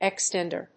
/ˈɛˌkstɛndɝ(米国英語), ˈeˌkstendɜ:(英国英語)/